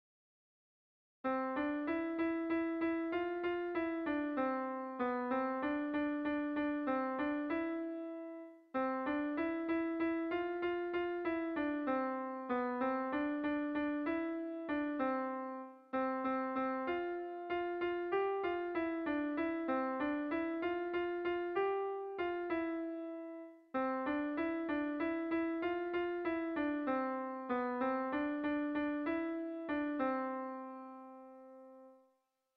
Zortziko handia (hg) / Lau puntuko handia (ip)
A1A2BA2